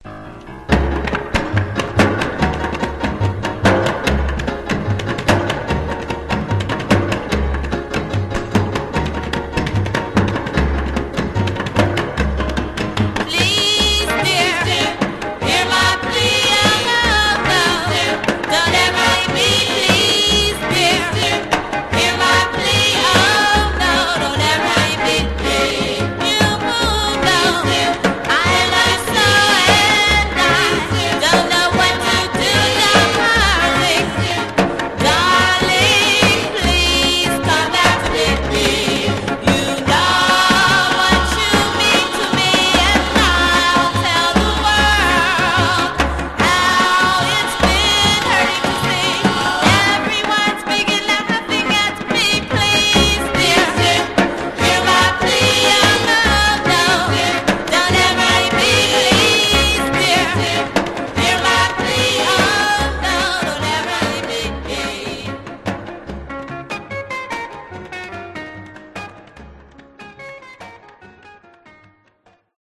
Genre: Girl Group
has a terrific uptempo number on the flip.